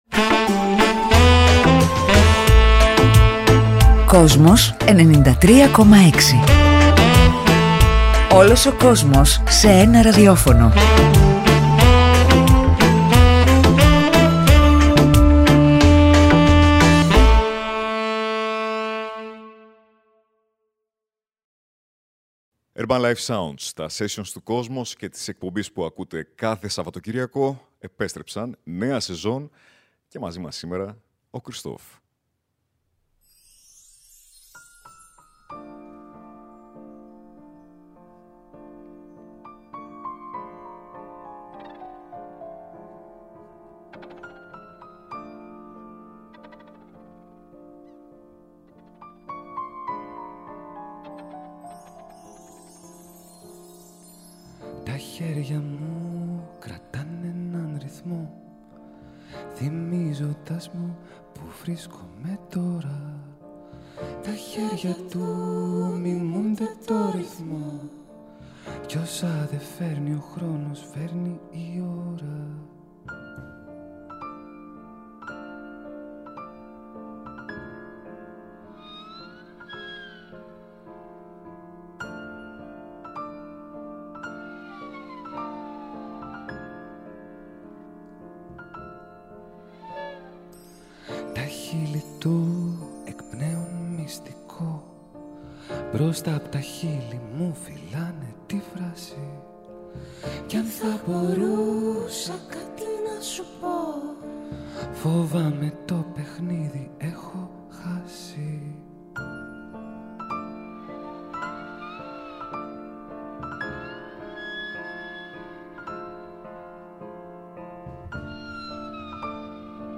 Αυτή την Κυριακή ήρθε στα στούντιο της ΕΡΤ
piano version
Συνεντεύξεις